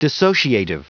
Prononciation du mot dissociative en anglais (fichier audio)
Prononciation du mot : dissociative